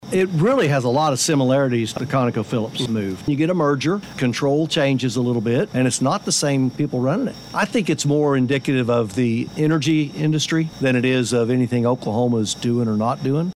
Fresh from the Bartlesville Chamber of Commerce "Eggs and Issues" event on Friday, where Representative John B. Kane, Senator Julie Daniels, and Representative Judd Strom met with constituents, the trio joined us in studio for KWON Radio's CAPITOL CALL program powered by Phillips 66